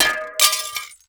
GLASS_Window_Break_11_mono.wav